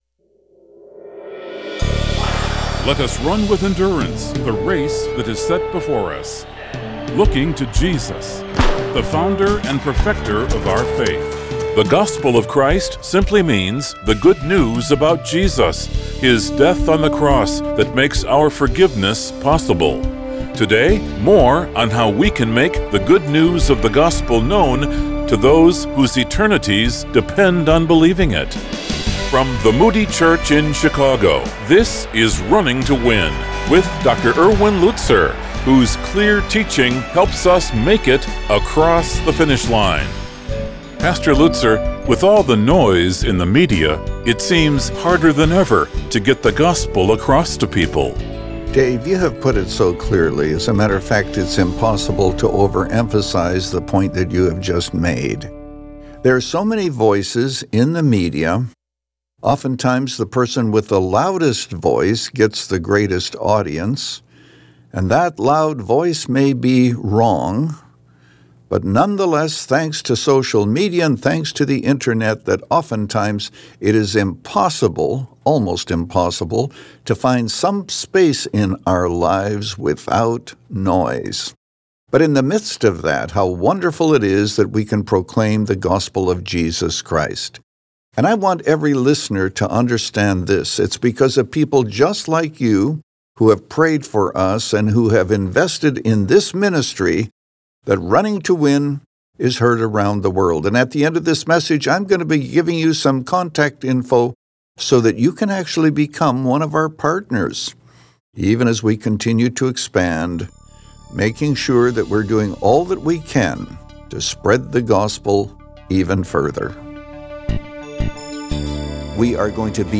Since 2011, this 25-minute program has provided a Godward focus and features listeners’ questions.